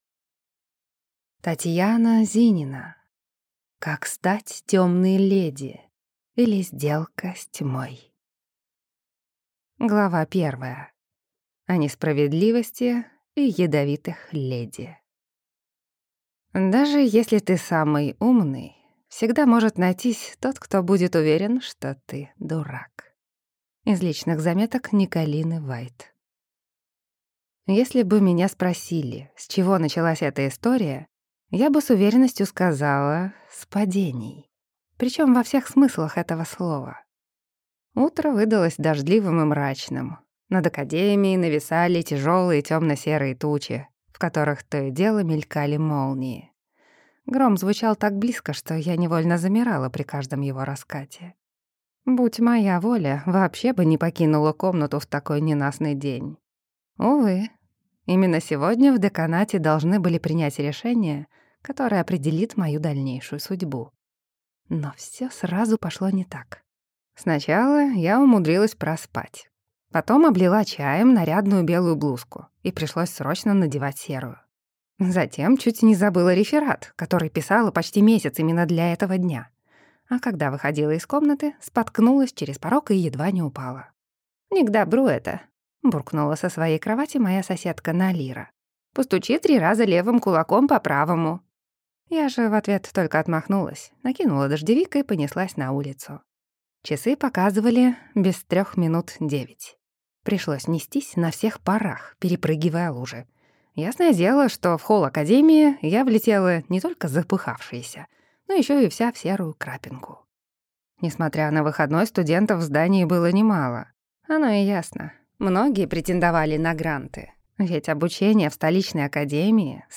Аудиокнига Как стать тёмной леди, или Сделка с тьмой | Библиотека аудиокниг